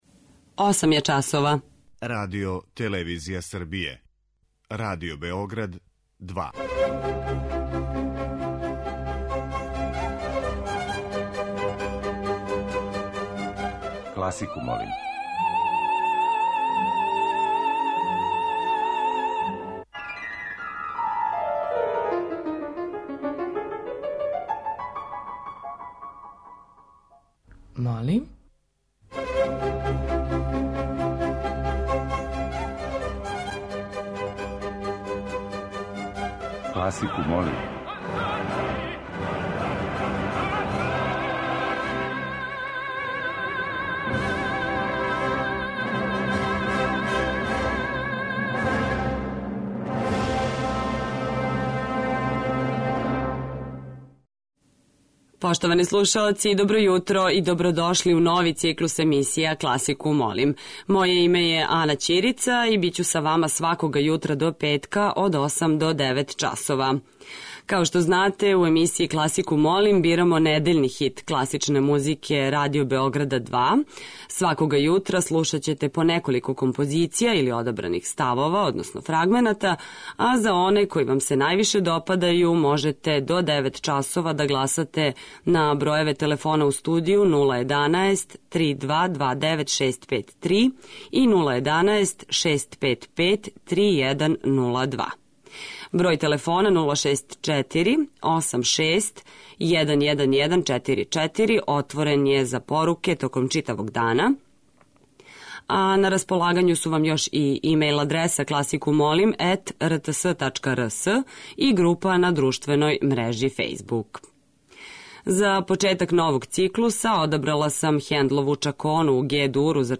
Листа предлога за хит класичне музике Радио Београда 2 ове седмице је обједињена композицијама за лимене дувачке инструменте.
Уживо вођена емисија, окренута широком кругу љубитеља музике, разноврсног је садржаја, који се огледа у подједнакој заступљености свих музичких стилова, епоха и жанрова. Уредници (истовремено и водитељи) смењују се на недељу дана и од понедељка до четвртка слушаоцима представљају свој избор краћих композиција за које може да се гласа телефоном, поруком, имејлом или у ФБ групи.